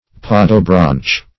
Search Result for " podobranch" : The Collaborative International Dictionary of English v.0.48: Podobranch \Pod"o*branch\, n. [See Podo- , and Branchia .]